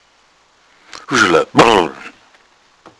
Ergibt im Zungenschlag "hu-schala".